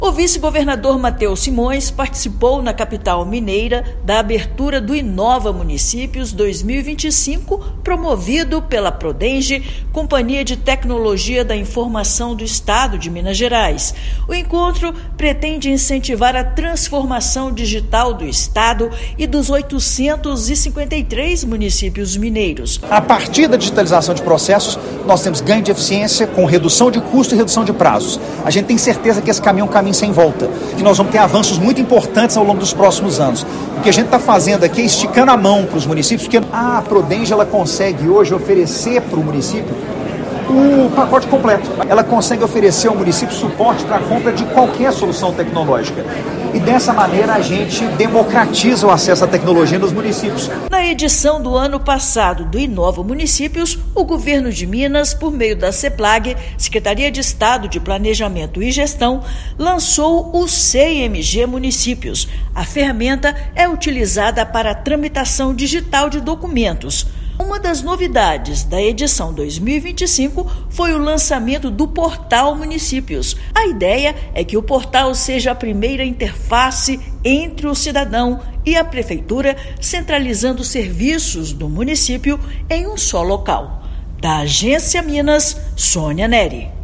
Evento anual promovido pela Prodemge reúne autoridades, servidores públicos e lideranças em torno da tecnologia para administração pública. Ouça matéria de rádio.